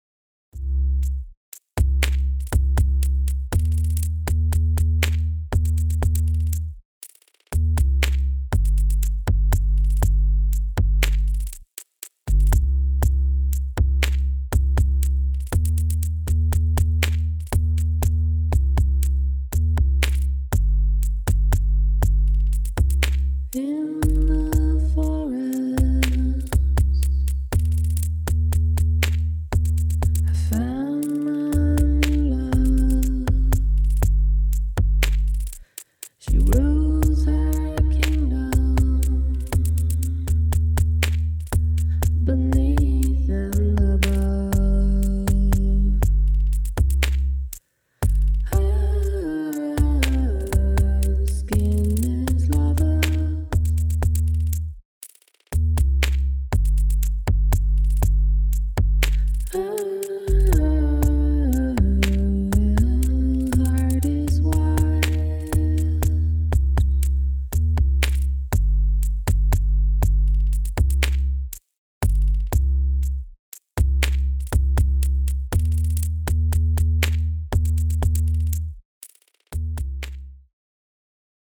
Musik mit Pilzen
Dann haben sie Pilze an den Synthesizer angeschlossen und den Bass, das Wummern und die flächigen Sounds aufgenommen.